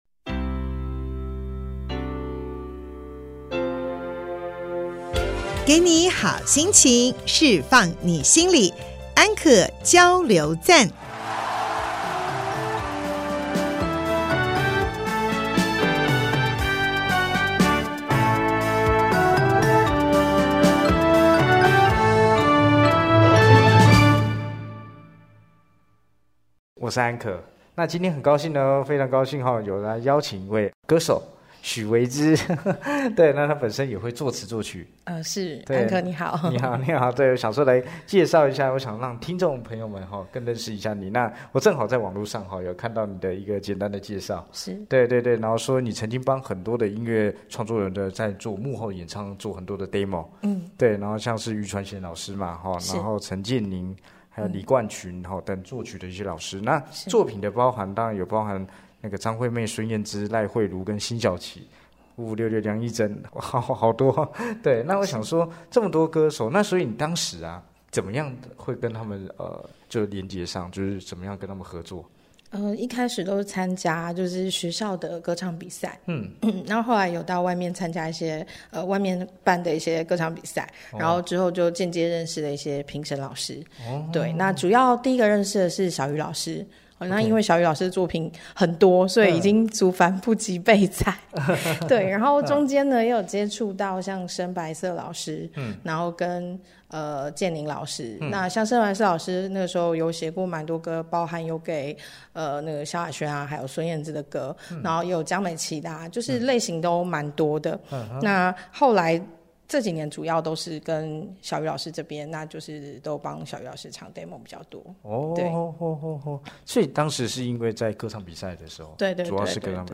節目裡有生活點滴的分享、各界專業人物的心靈層面探析及人物專訪